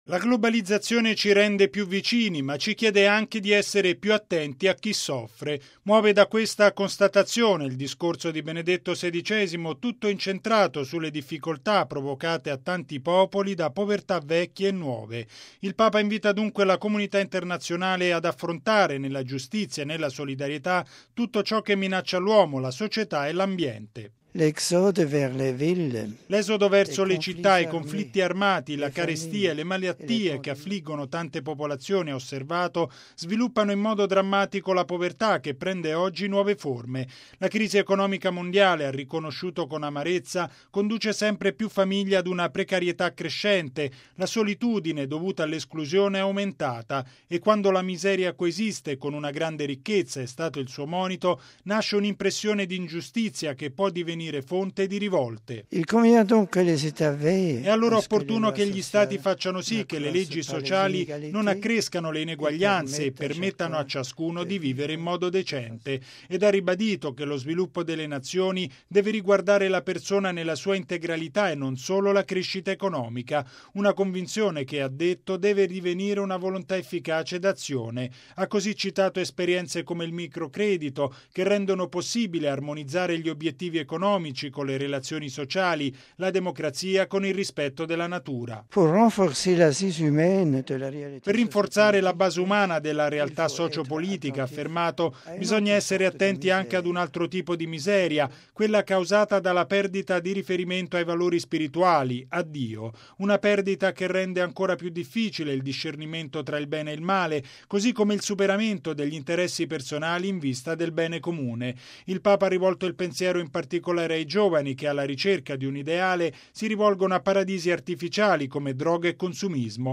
Il Papa ha quindi ribadito che gli Stati devono impegnarsi a garantire la libertà religiosa, che aiuta a formare un’identità forte della persona umana. Il servizio